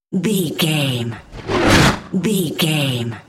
Whoosh fast airy cinematic
Sound Effects
Fast
futuristic
intense